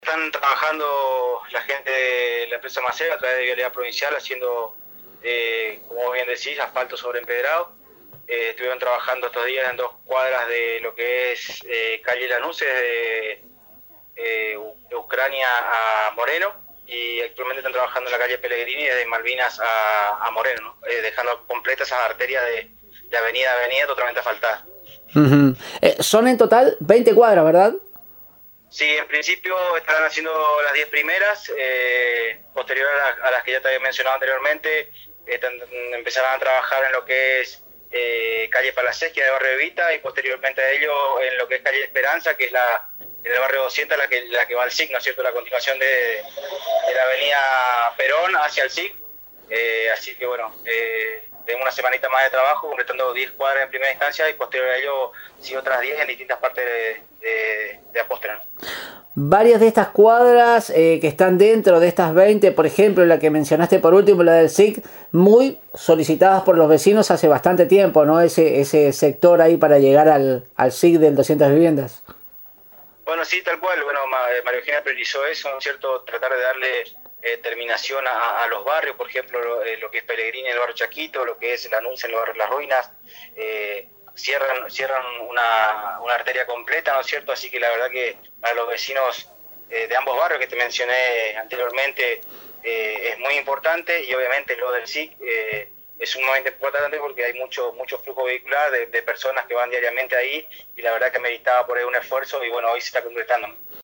En comunicación telefónica con Radio Elemental